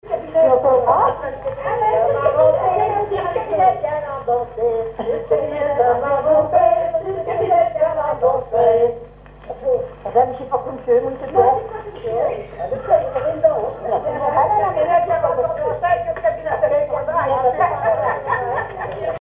Couplets à danser
danse : branle
collecte en Vendée
Veillée de chansons